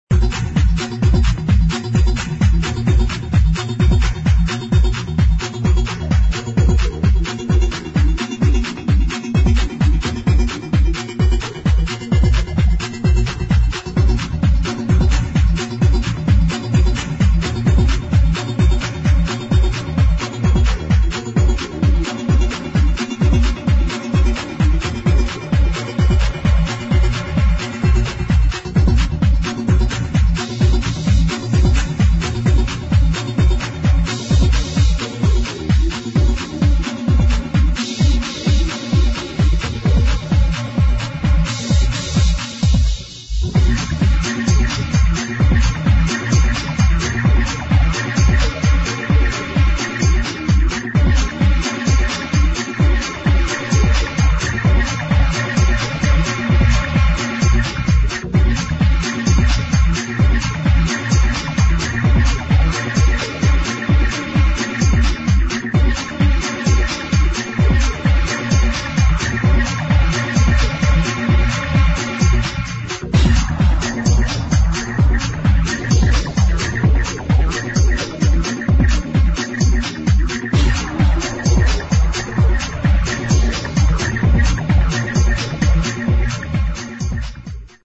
[ TECHNO / TECH HOUSE ]